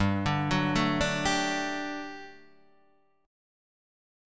G Major 7th Suspended 2nd